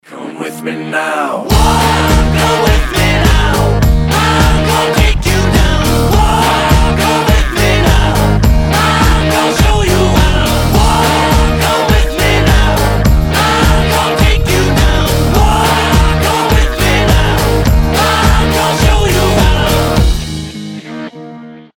• Качество: 320, Stereo
ритмичные
alternative